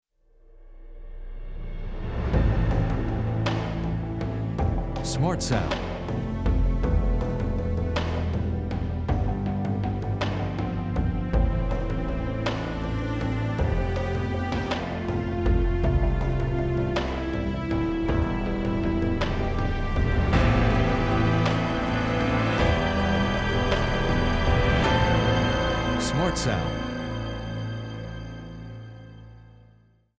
Will you listen to several short pieces of background music that we are considering using in a 30 second trailer/advert for the pilot programme?